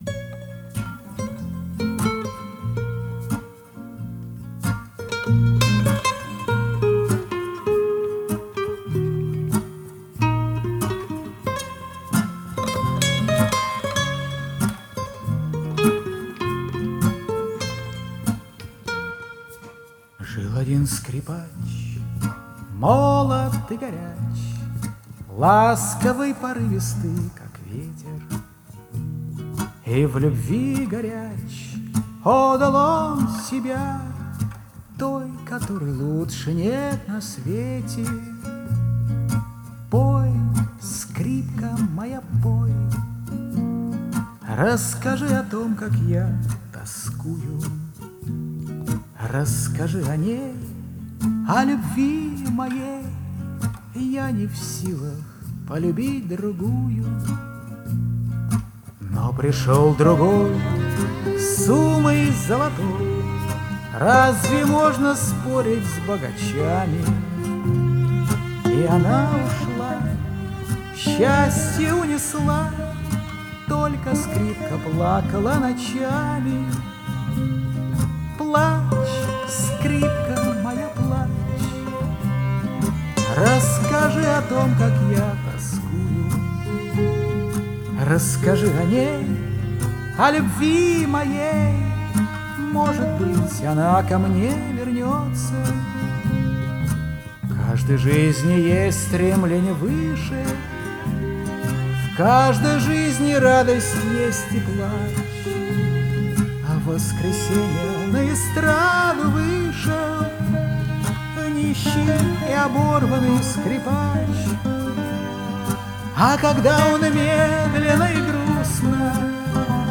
Дуэт двух мульти-инструменталистов
Жанр: Фолк, Шансон, Романс, Акустика